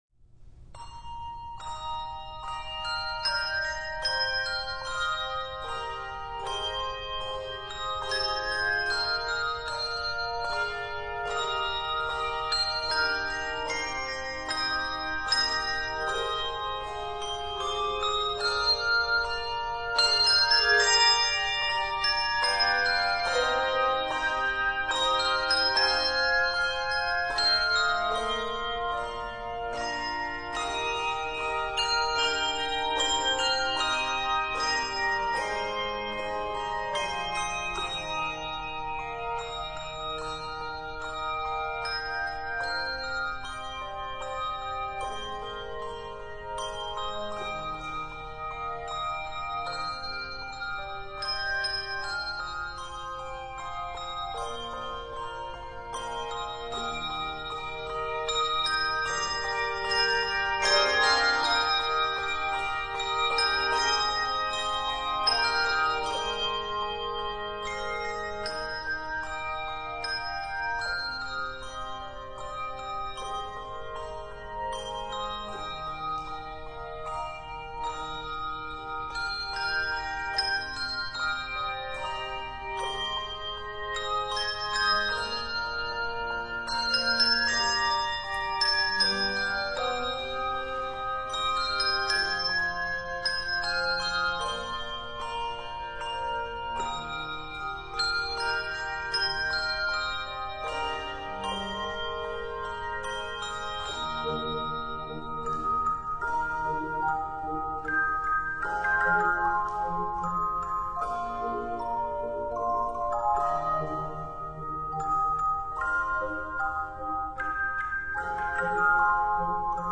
Voicing: Handbells